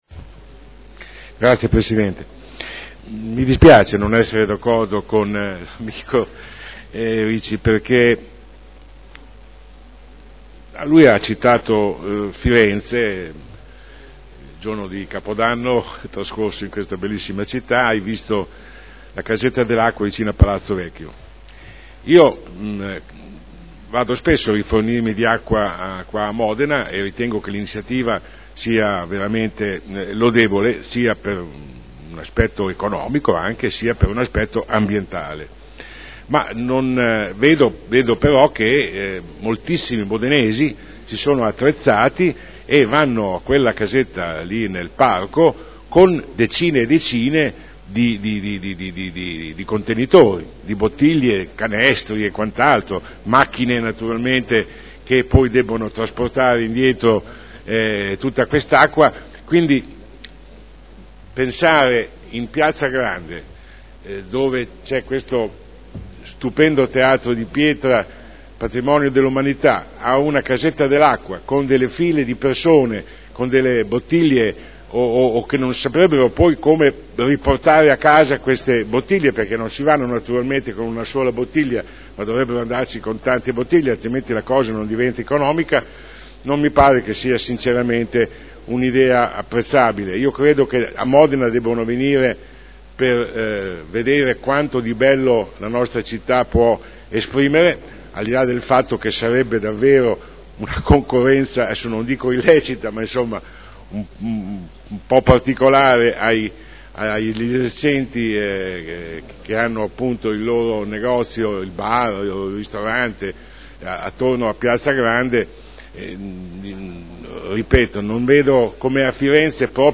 Sandro Bellei — Sito Audio Consiglio Comunale
Dibattito. Interrogazione dei consiglieri Sala, Cotrino, Goldoni (P.D.) e Ricci (Sinistra per Modena) avente per oggetto: “Dati utenze della “Casetta dell’acqua””.